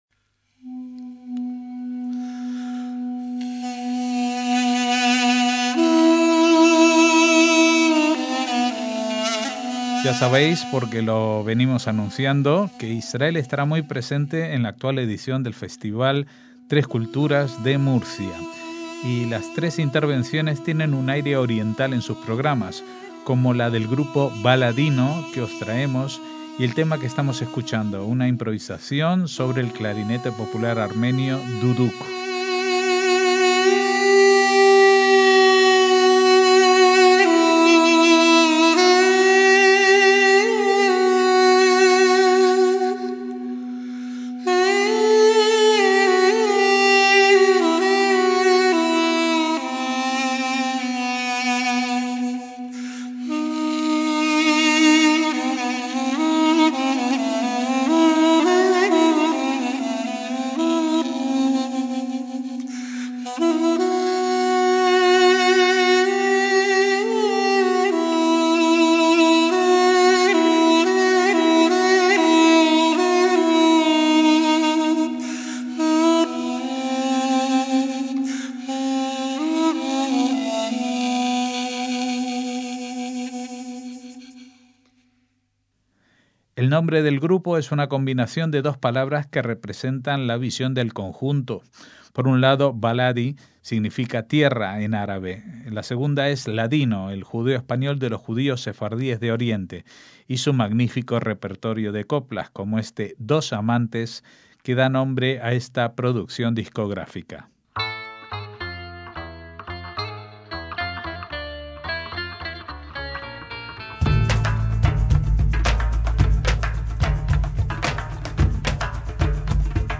PONLE NOTAS - Ya sabéis, porque los venimos anunciando, que Israel estará muy presente en la actual edición del Festival Tres Culturas de Murcia. Y las tres intervenciones tienen un aire oriental en sus programas, como la del grupo Baladino que os traemos.